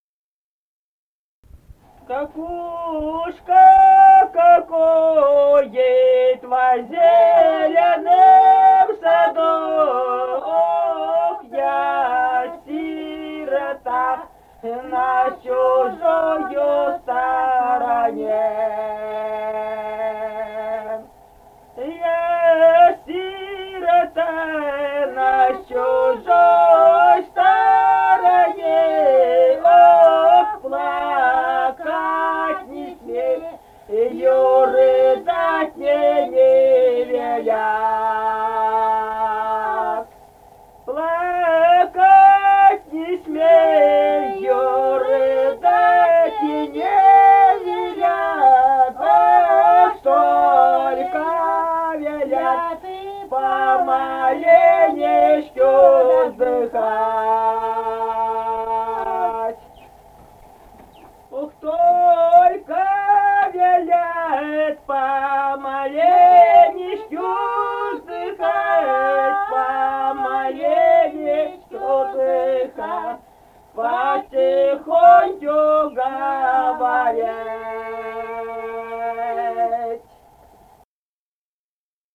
Республика Казахстан, Восточно-Казахстанская обл., Катон-Карагайский р-н, с. Язовая, июль 1978.